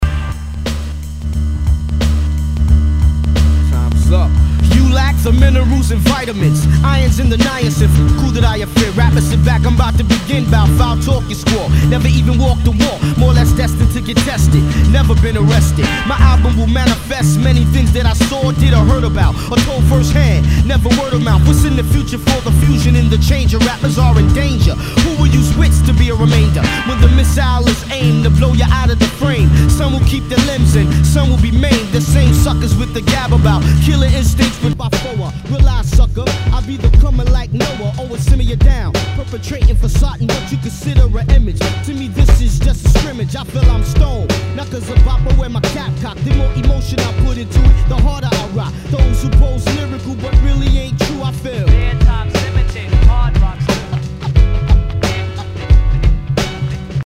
HIPHOP/R&B
全体にチリノイズが入ります